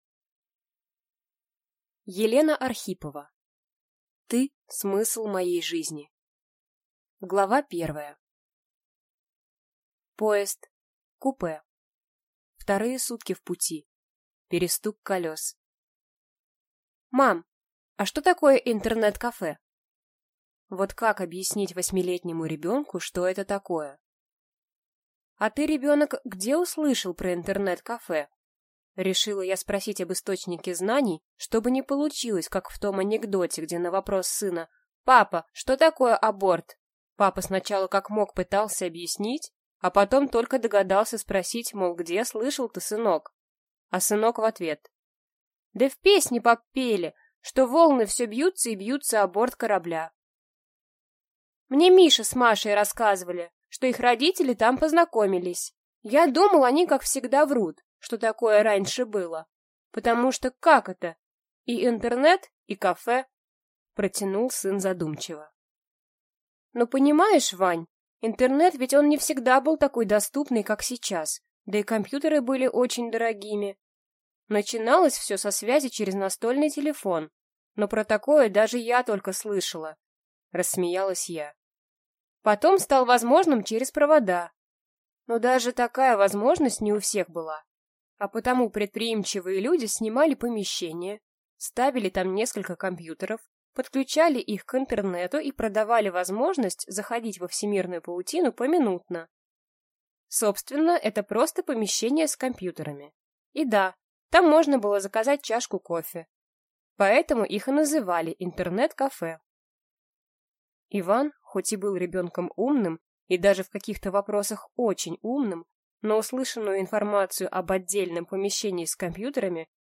Аудиокнига Ты смысл моей жизни | Библиотека аудиокниг